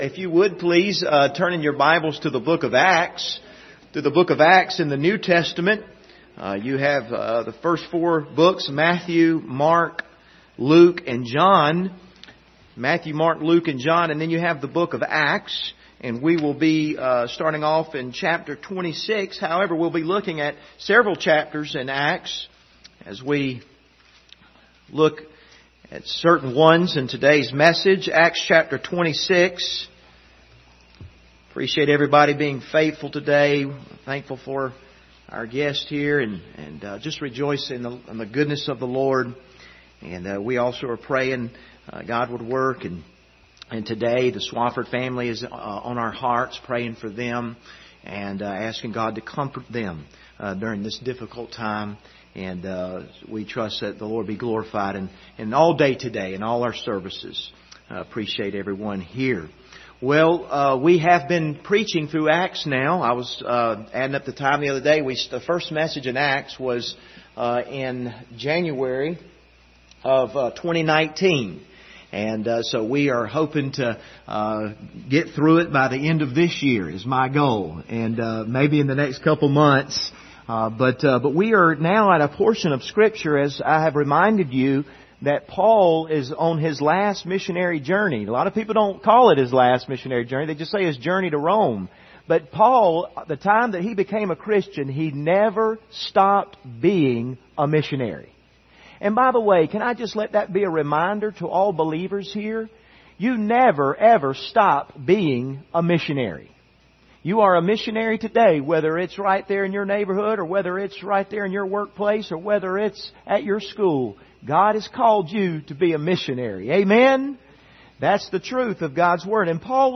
Service Type: Sunday Morning Topics: salvation